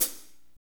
HAT F S P0EL.wav